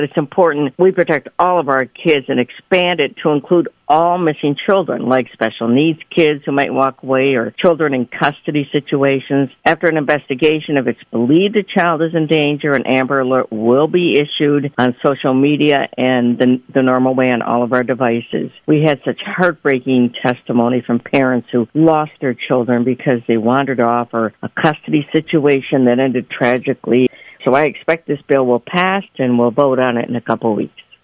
This full conversation is available to listen to and download on the local interviews portion of our website. State Representative Kathy Schmaltz regularly joins A.M. Jackson on Fridays.